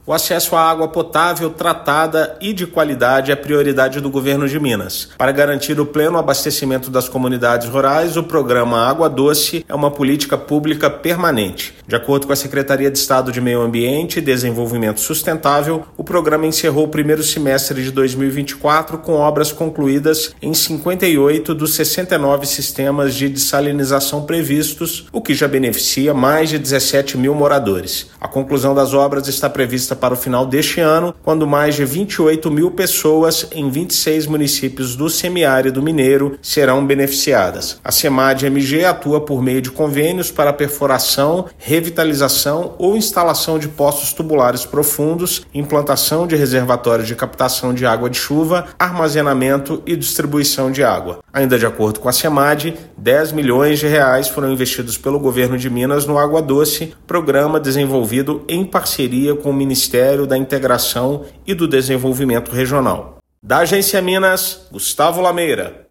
Programa Água Doce finalizou o primeiro semestre de 2024 com 84% de suas obras concluídas; comunidades beneficiadas relatam melhoria na qualidade de vida. Ouça matéria de rádio.